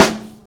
64FUNNY-SD-R.wav